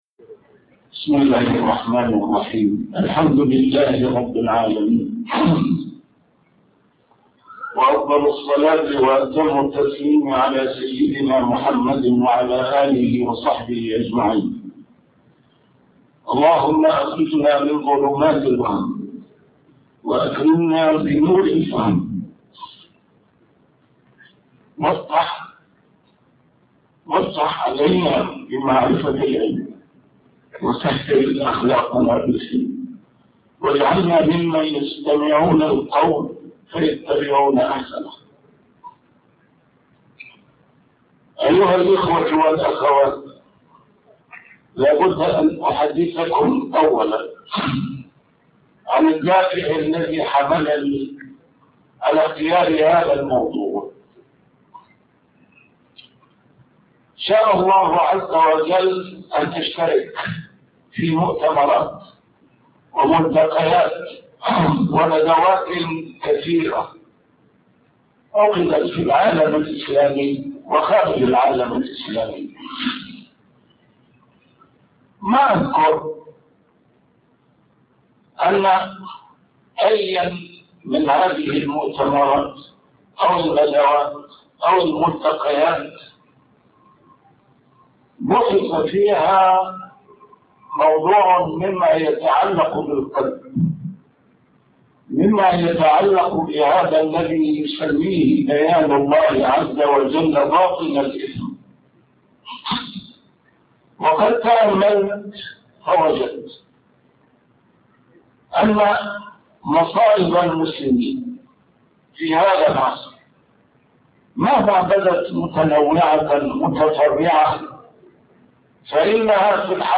A MARTYR SCHOLAR: IMAM MUHAMMAD SAEED RAMADAN AL-BOUTI - الدروس العلمية - محاضرات متفرقة في مناسبات مختلفة - تمكن حلاوة الهوى من القلب هو الداء العضال | محاضرة بيروت